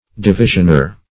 Search Result for " divisionor" : The Collaborative International Dictionary of English v.0.48: Divisionor \Di*vi"sion*or\, n. One who divides or makes division.